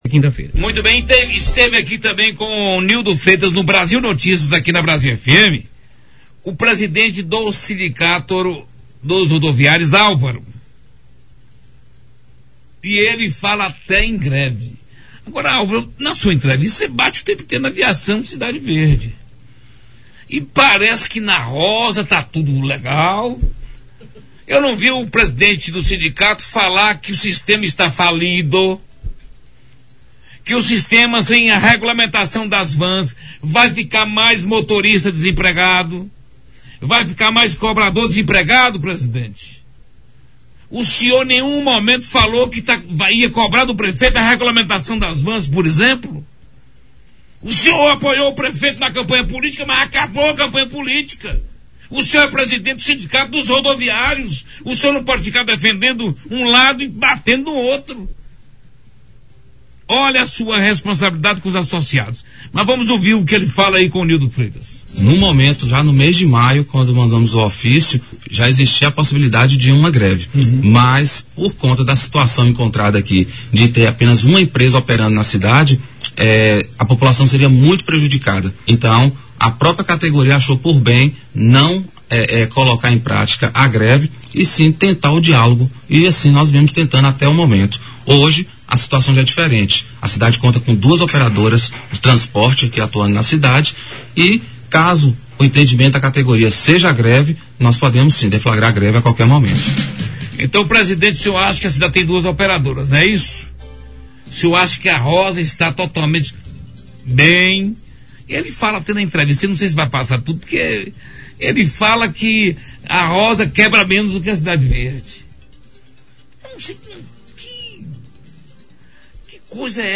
Entrevista no Redação Brasil